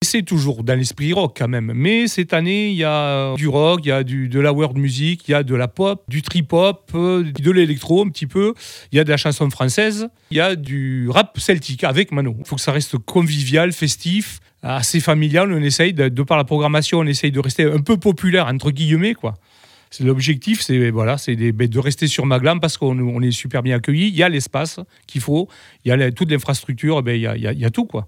Coup de projecteur sur la 3ème édition du festival Quai des Sons de Magland avec un de ses organisateurs